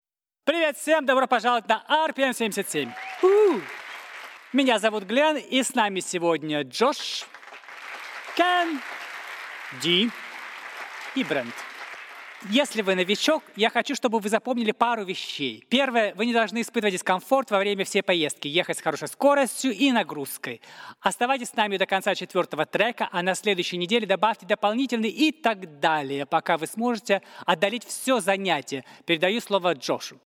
Russian LesMills Cycling Showreel
Male
Bright
Down To Earth
Friendly